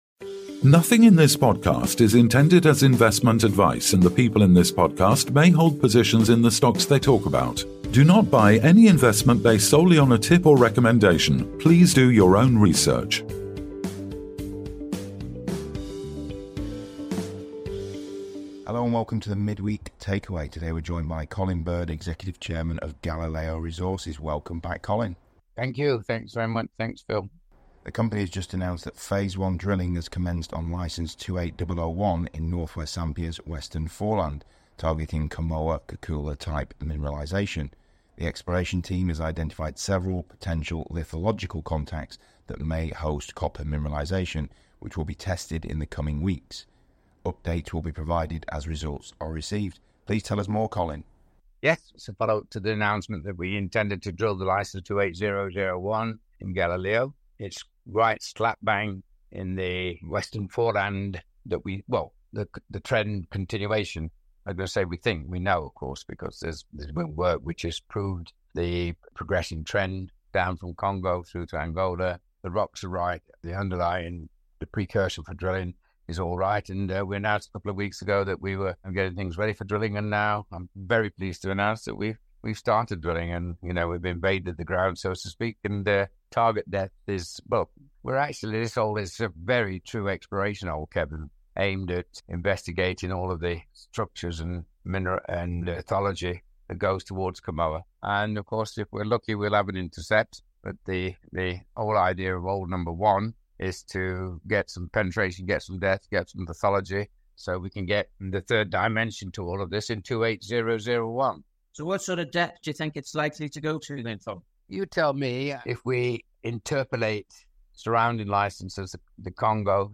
He also shares insights on the exploration strategy and what this could mean for the company’s future. Don’t miss this detailed conversation on Galileo’s promising steps forward in Zambia.